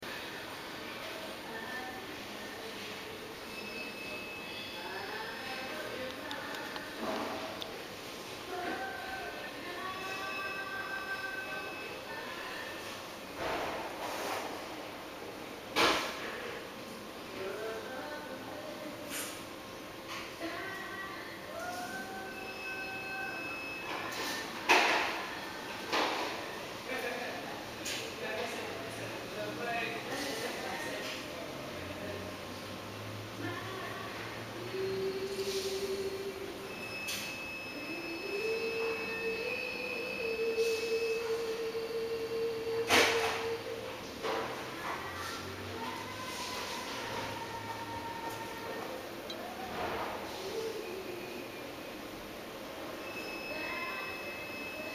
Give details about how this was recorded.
As I sat waiting for the sonogram, I heard music floating above the ambience of the hospital.